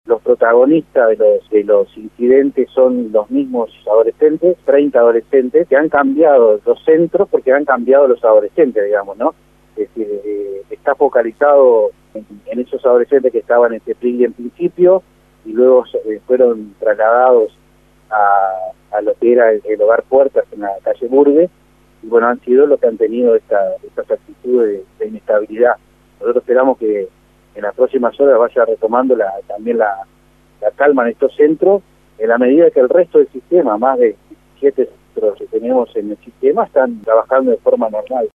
El edil del Partido de la Concertación, Diego Rodríguez dijo a 810 Vivo que no recibieron la información detallada que esperaban por lo que votaron en contra de esta línea de crédito.
Escuche al edil